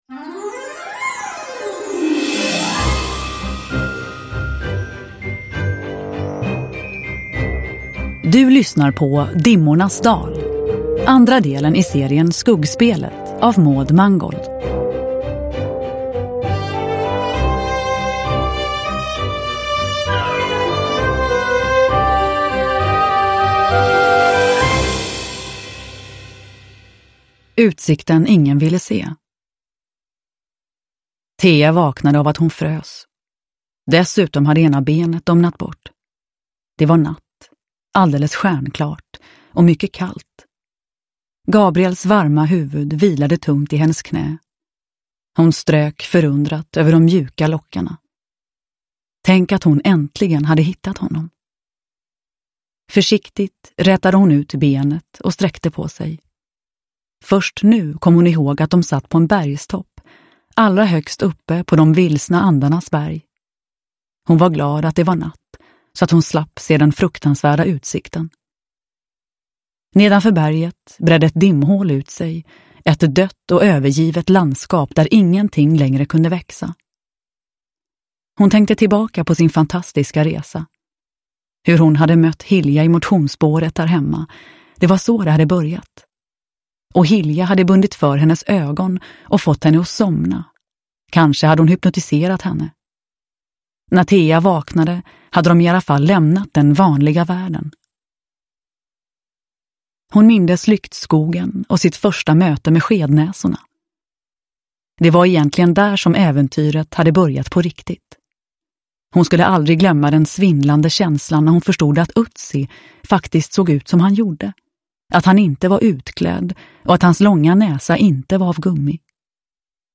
Dimmornas dal – Ljudbok – Laddas ner
Uppläsare: Nina Zanjani